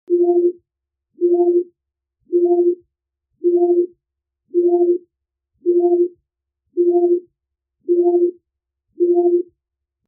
دانلود آهنگ موج 6 از افکت صوتی طبیعت و محیط
دانلود صدای موج 6 از ساعد نیوز با لینک مستقیم و کیفیت بالا
جلوه های صوتی